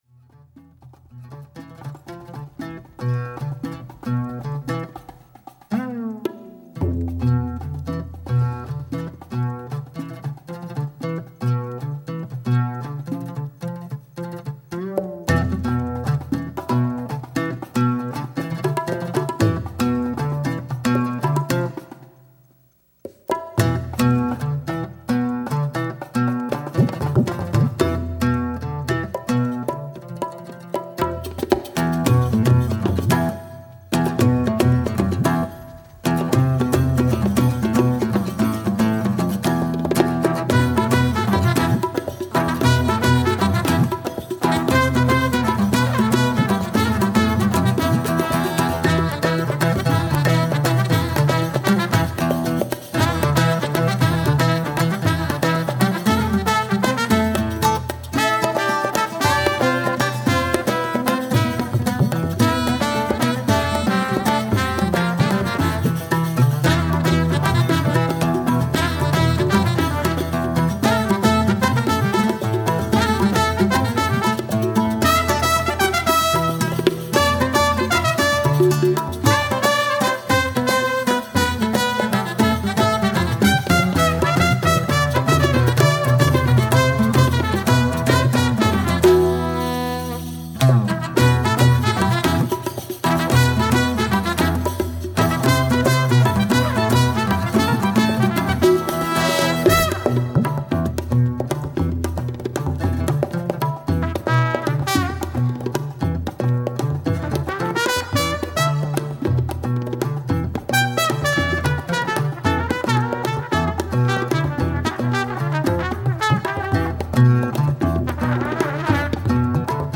Live at The Hopkins Center 2/27/07